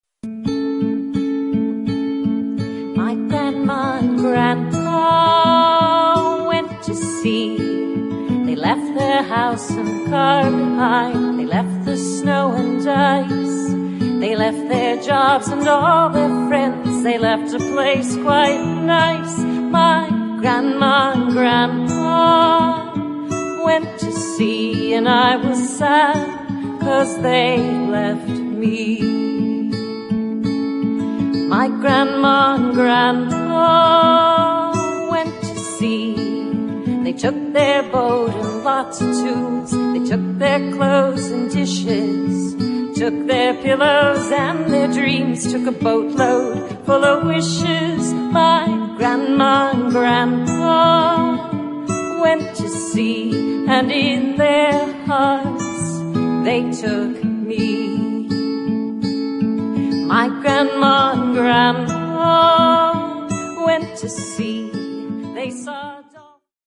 boating music